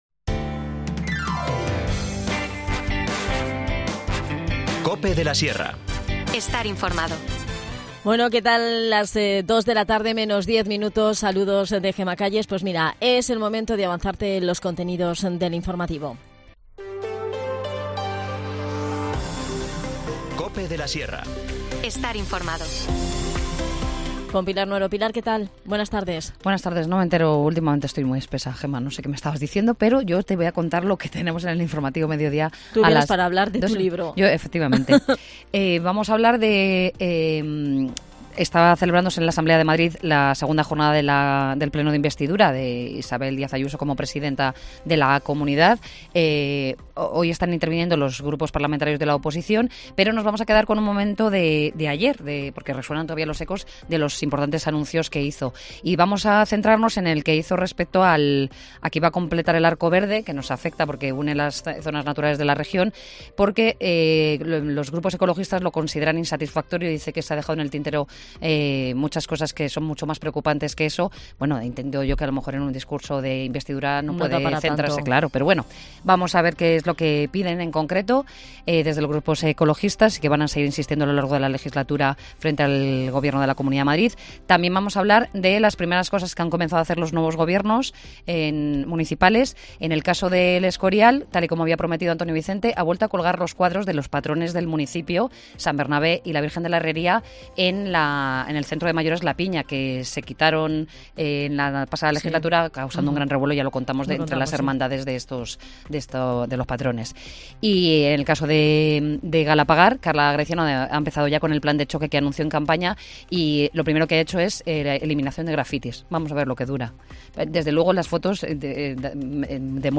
Adan Martínez, concejal de Comunicación en Collado Villalba, Capital de la Sierra, nos visita para hablarnos de toda la actualidad del municipio que pasa por comentarnos las novedades y los cambios en el reparto de competencias en el nuevo Gobierno municipal.